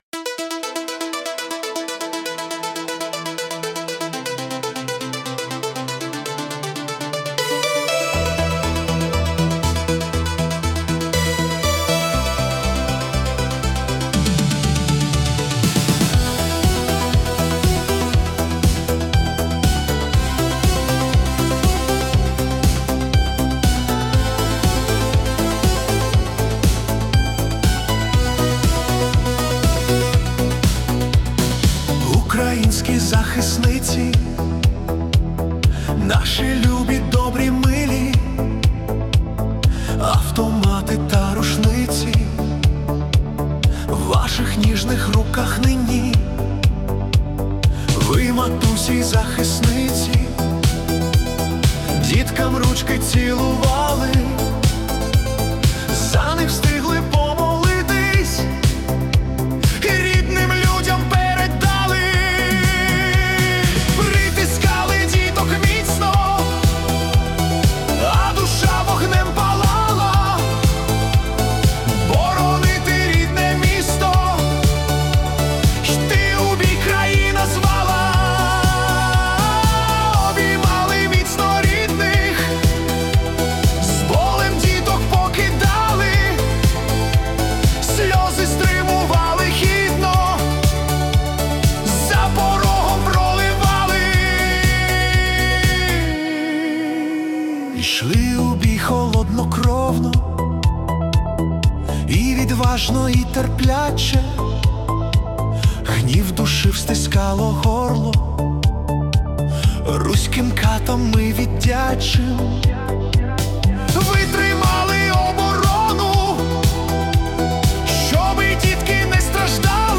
🎵 Жанр: Italo Disco / Tribute
це енергійна і водночас лірична пісня (120 BPM)